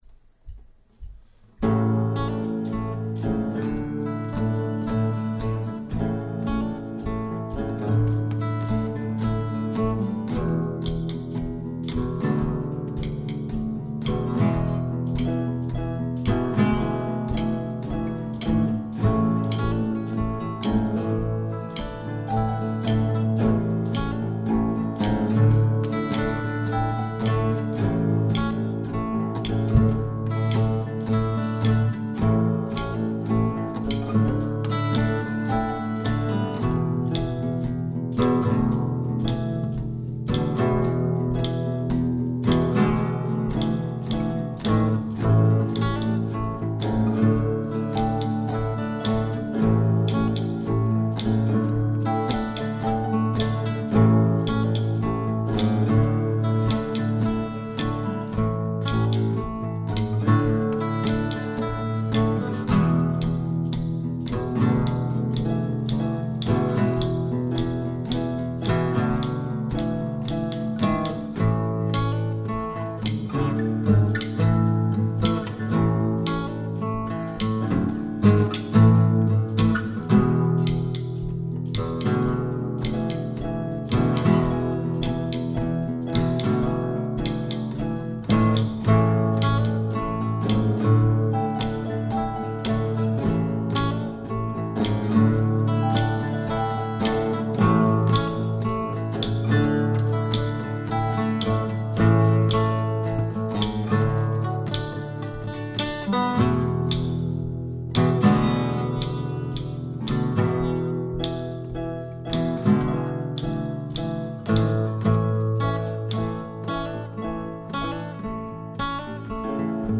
Autumn Orange, (instrumental music and image)